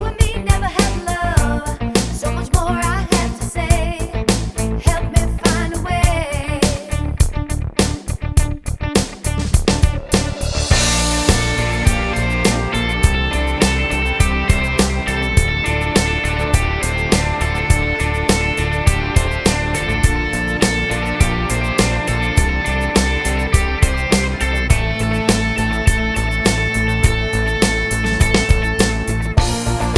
Minus All Guitars Pop (2000s) 4:05 Buy £1.50